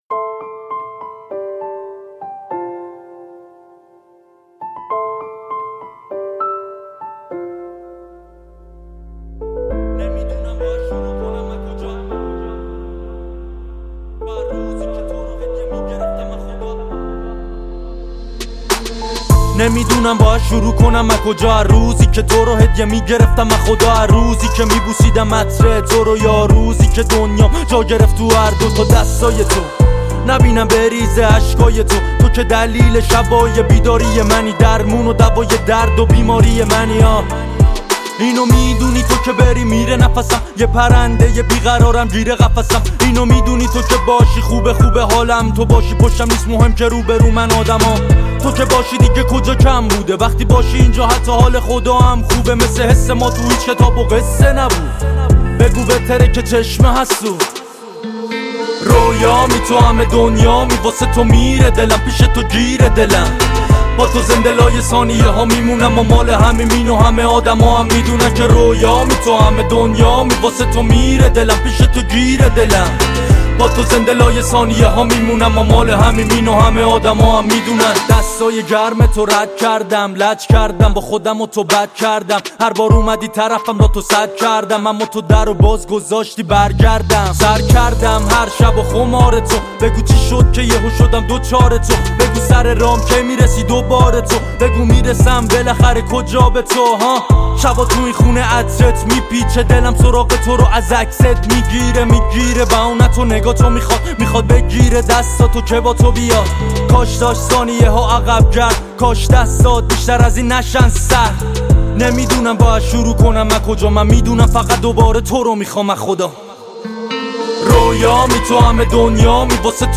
دانلود اهنگ دپ رپ فارس غمگین داغون کننده فاز سنگین